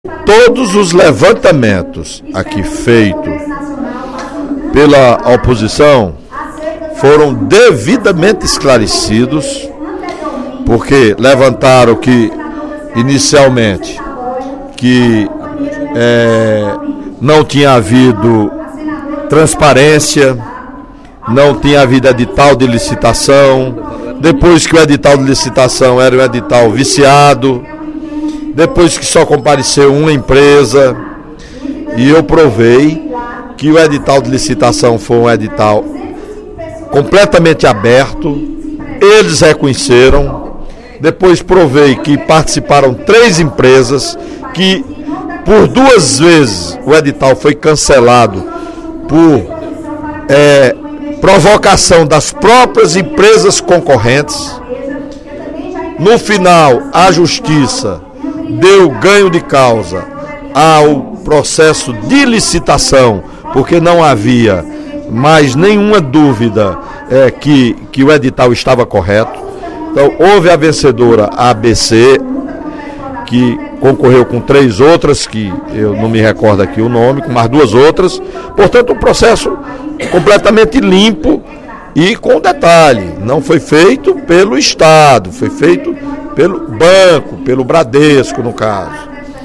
O deputado Welington Landim (PSB) disse, nesta quarta-feira (04/04) em plenário, que já está devidamente esclarecida para a sociedade a legalidade dos empréstimos consignados para os servidores públicos do Estado. Para o parlamentar, está havendo uma tentativa de “requentar” o assunto, uma vez que todas as informações sobre a legalidade das transações bancárias foram devidamente repassadas pelo Governo.